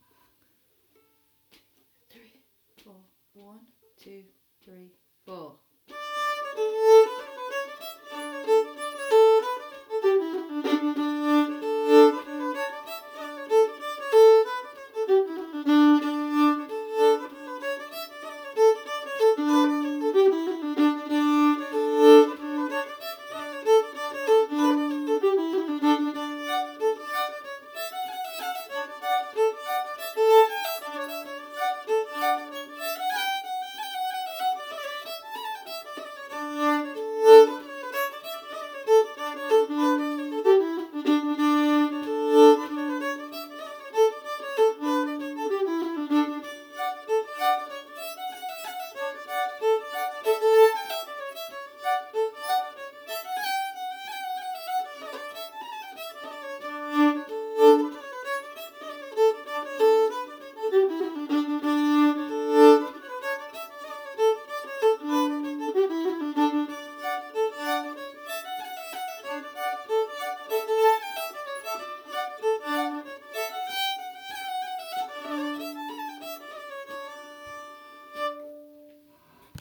Whole Tune